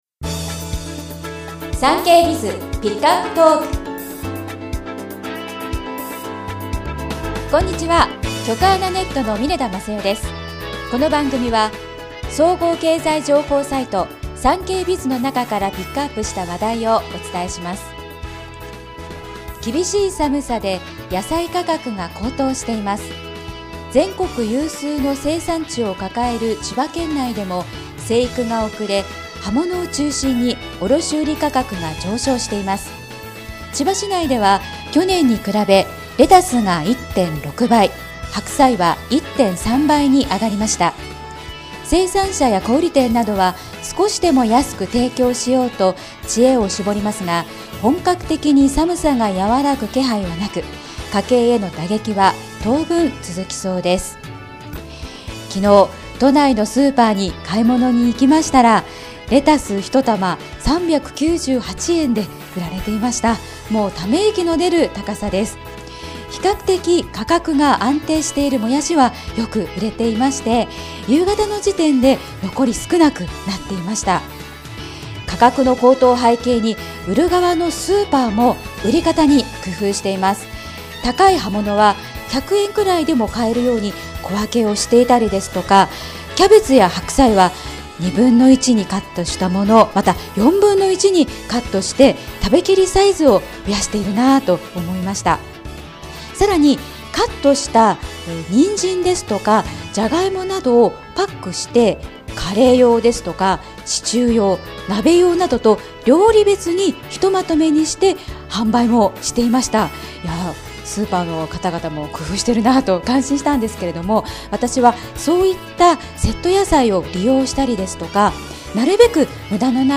全国240名の登録がある局アナ経験者がお届けする番組「JKNTV」